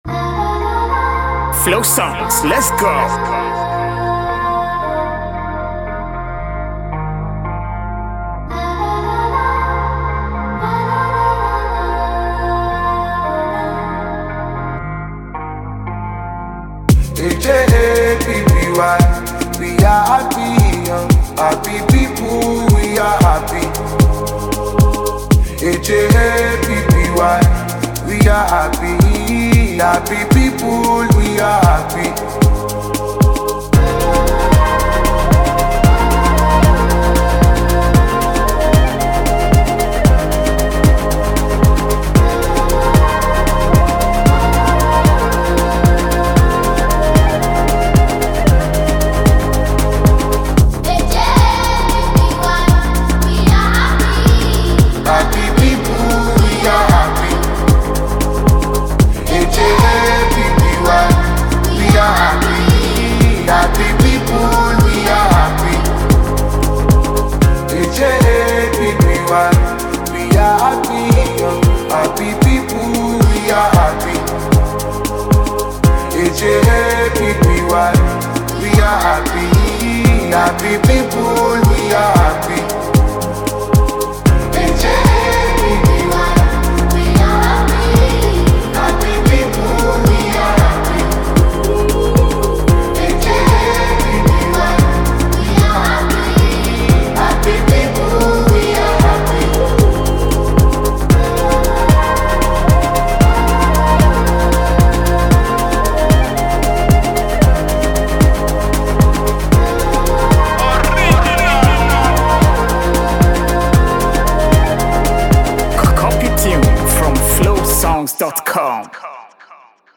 It is a catchy and lively melody.
AFROPOP and Afrobeat